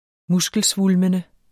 muskelsvulmende adjektiv Bøjning -, - Udtale [ ˈmusgəlˌsvulmənə ] Betydninger 1. som fremstår stor eller stærk pga.